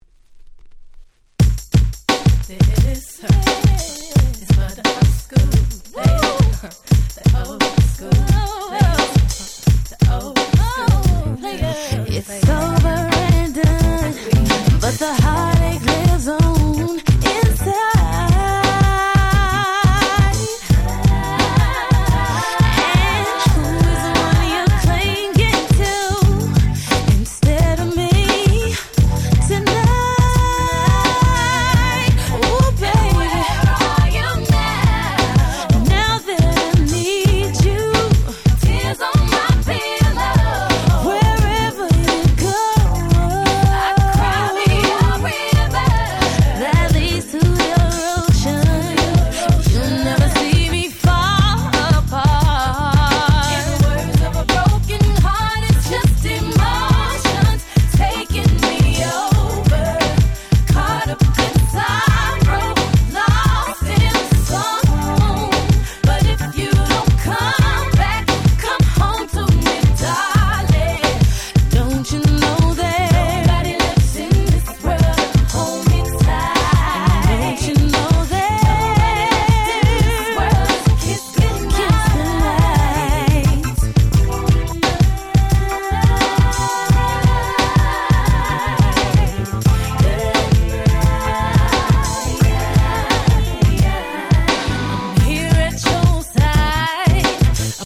01' Super Hit R&B !!